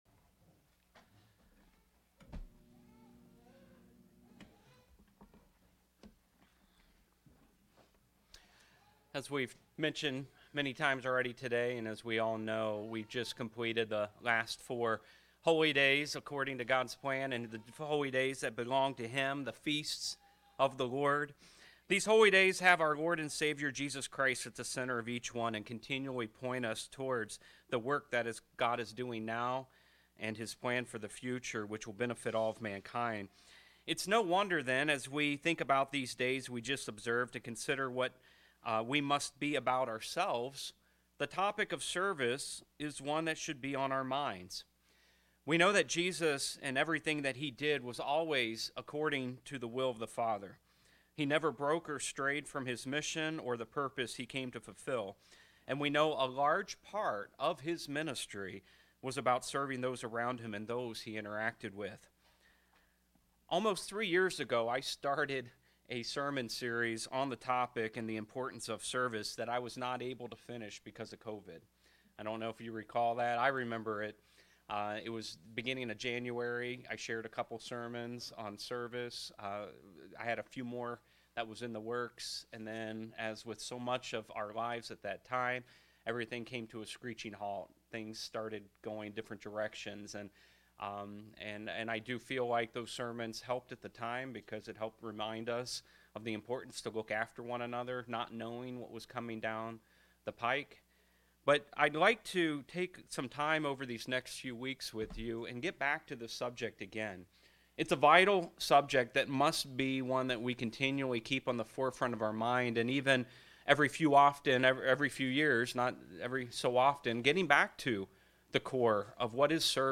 Part 1 in a series of sermons that will explore service and care within our local congregations. As a starting point, we must explore and understand Christ-centered service and consider how our approach to service should emulate that of our Lord and Savior.